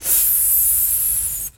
snake_hiss_01.wav